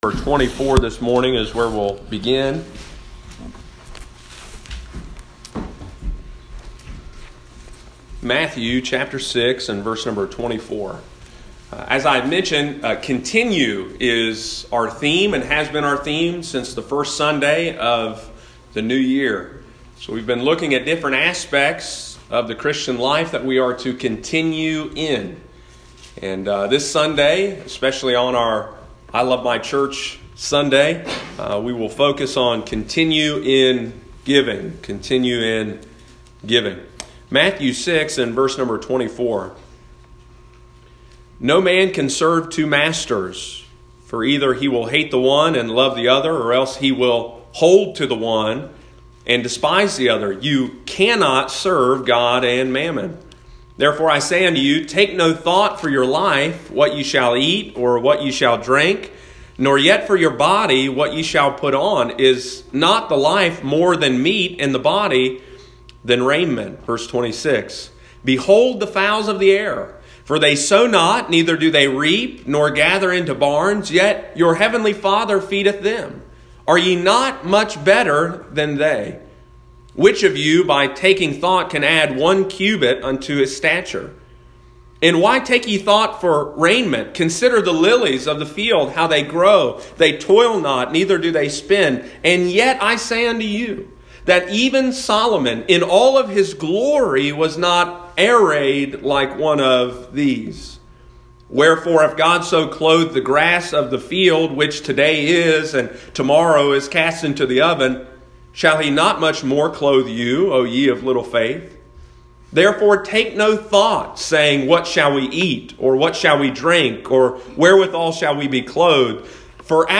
Sunday morning, March 1, 2020.